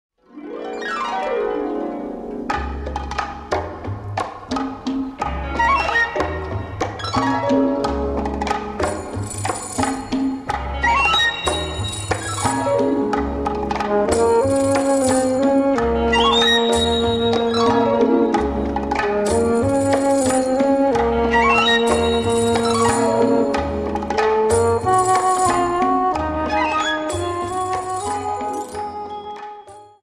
Dance: Rumba 23